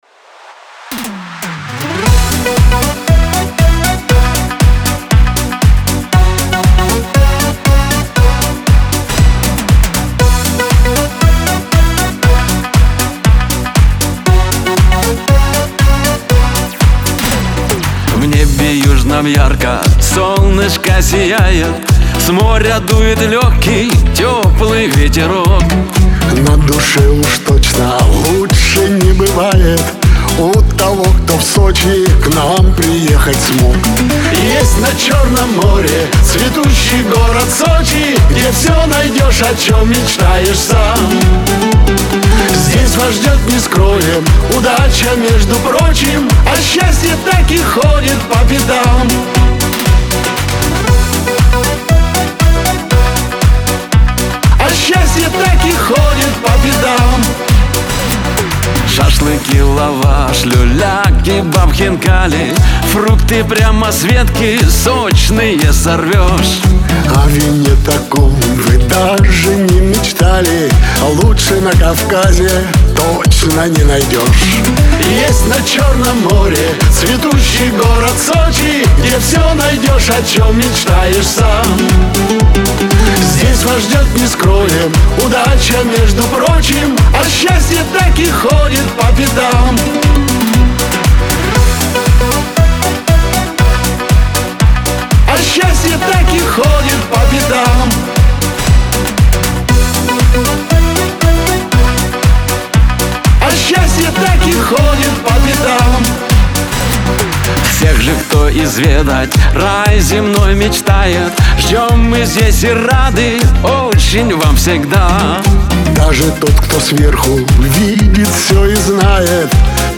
Веселая музыка
диско , pop